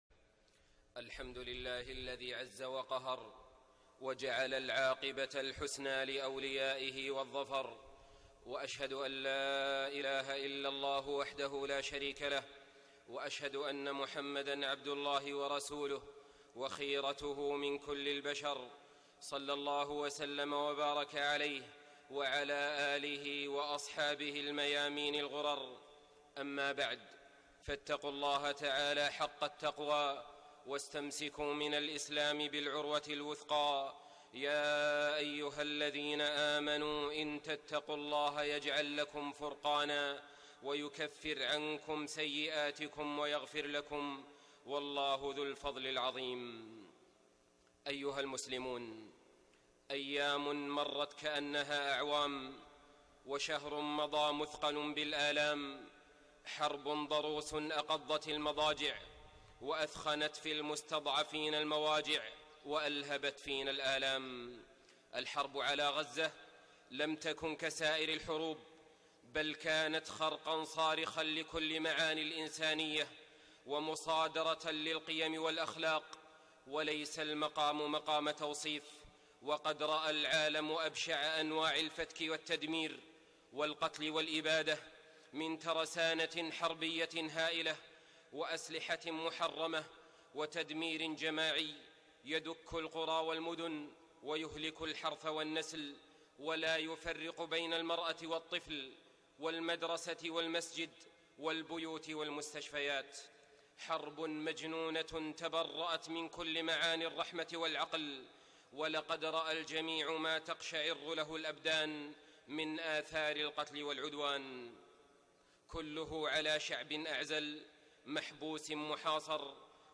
خطبة الجمعة 26 محرم 1430هـ > خطب الحرم المكي عام 1430 🕋 > خطب الحرم المكي 🕋 > المزيد - تلاوات الحرمين